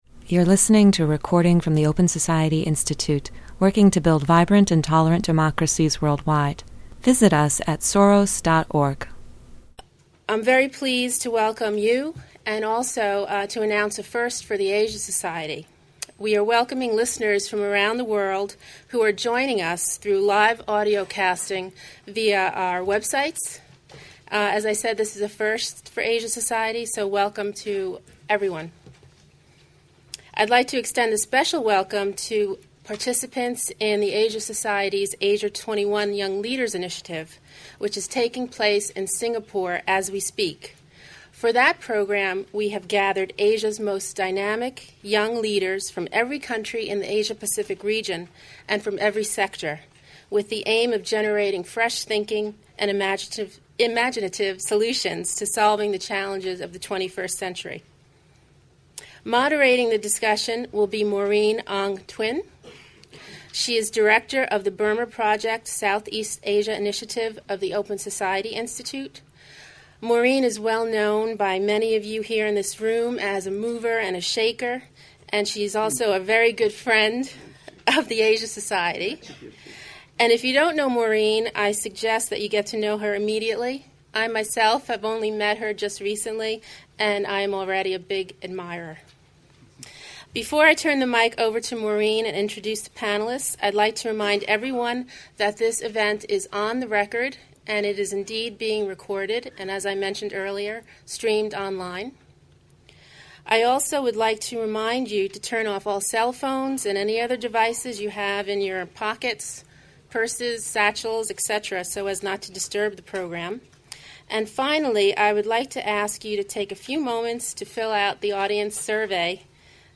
The Open Society Institute and Asia Society hosted a program at Asia Society on the current situation in Burma.